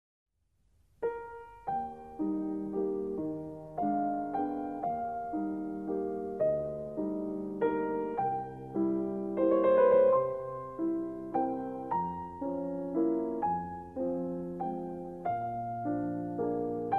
クラシックピアノの場合、踏み始めの軸は左手の１拍目、話すのは次の拍に混ざらない程度の直前、そして次に繋げます。
ダンパーペダルにより、顕著に音の伸びや優雅な音楽風潮漂うアンニュイ感、実感できたのではないでしょうか。